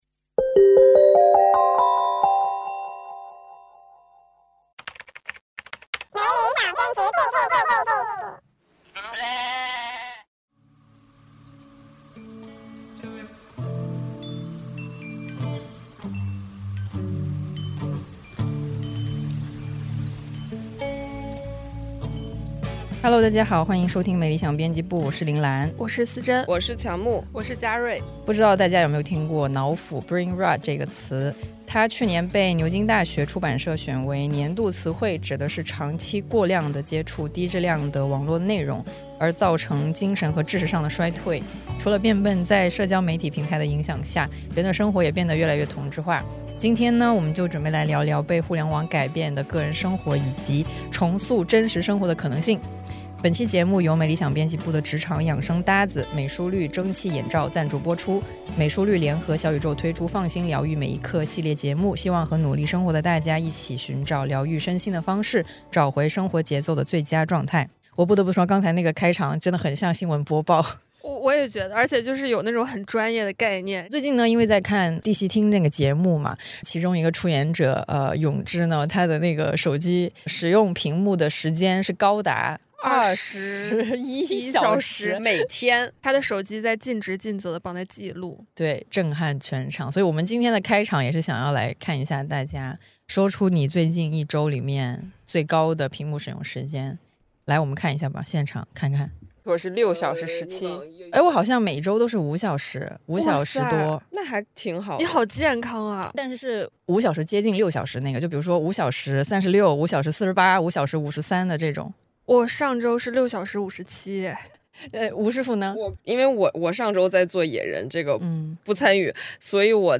「没理想编辑部」是由「看理想」新媒体部出品的谈话类生活文化播客，a.k.a编辑们的午饭闲聊精选集，欢迎入座。